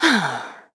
Xerah-Vox_Sigh.wav